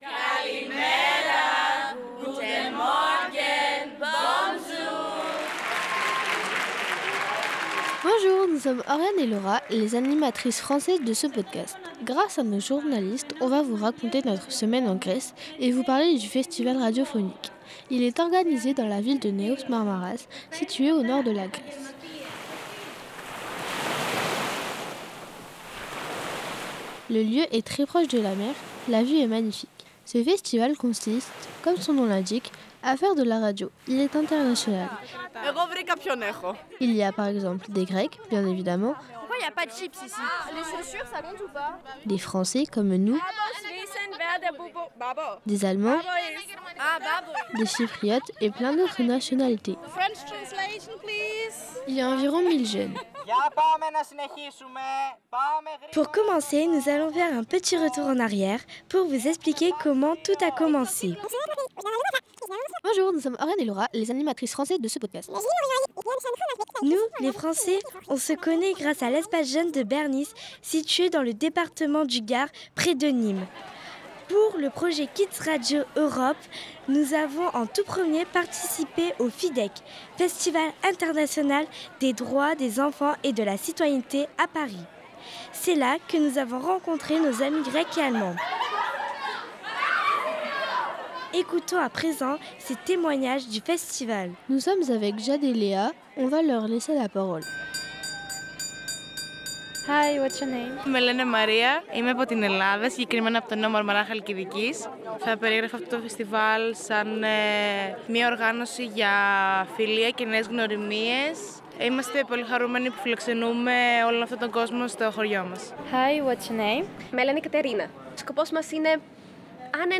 Kids radio Europe au festival des radios scolaires à Neos Marmaras
Ce podcast retrace leur expérience : des échanges avec les institutions locales, les organisateur·trices et les participant·es venu·es de toute la Grèce et de Chypre. À travers ce reportage collectif et multilangue, les auditeur·trices plongent dans les coulisses de l’événement, découvrent les enjeux portés par les radios scolaires et mesurent la portée d’un dialogue européen engagé entre jeunes.